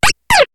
Cri de Scrutella dans Pokémon HOME.